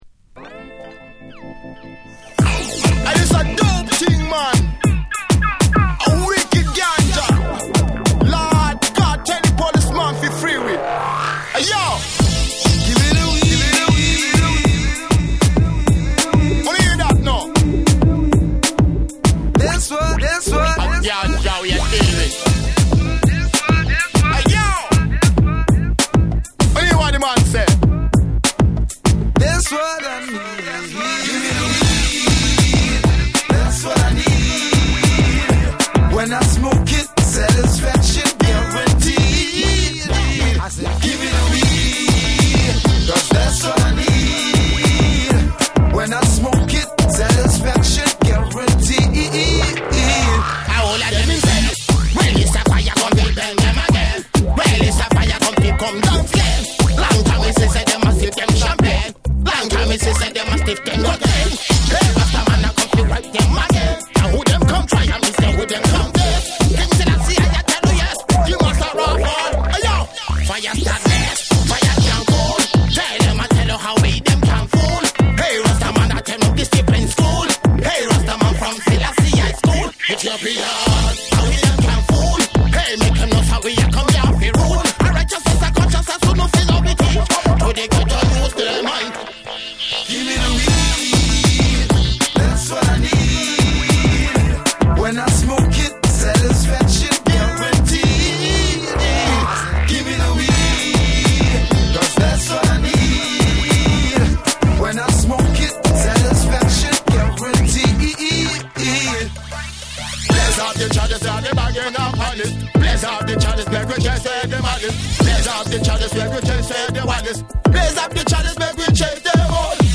BREAKBEATS / REGGAE & DUB